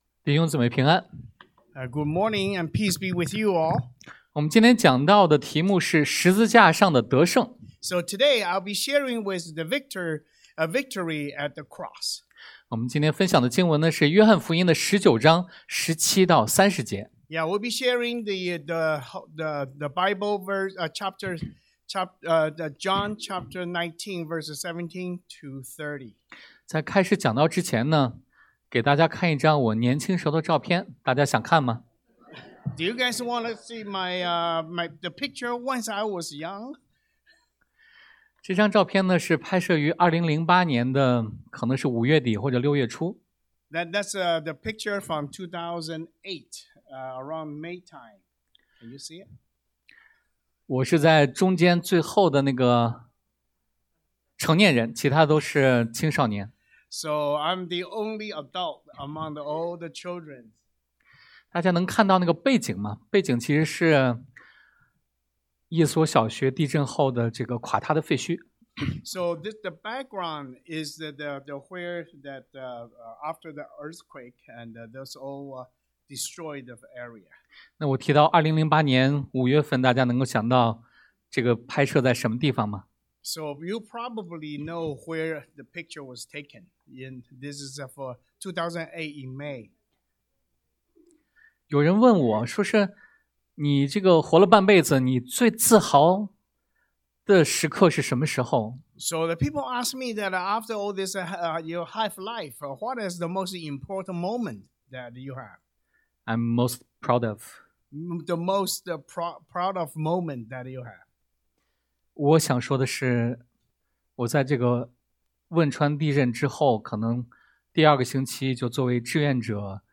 Passage: 約翰福音 John 19:17-30 Service Type: Sunday AM 神的主權在苦難中掌權 | God’s Sovereignty Rules in Suffering